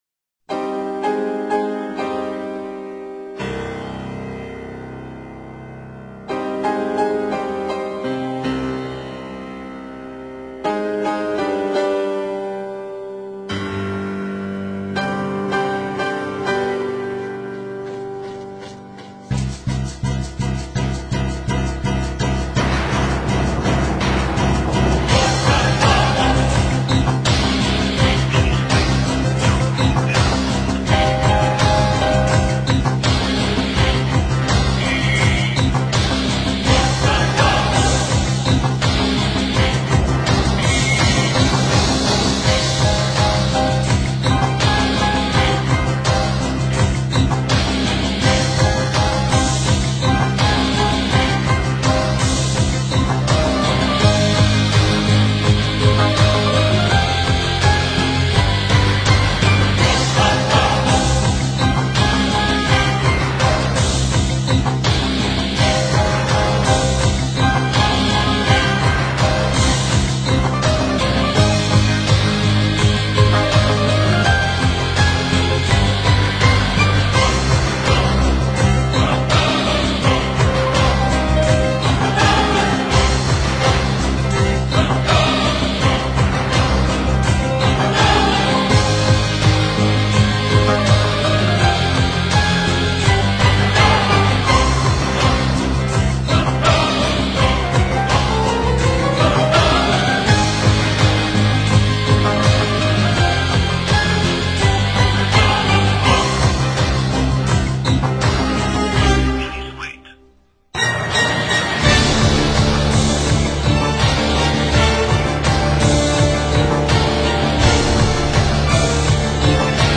九歲開始學習鋼琴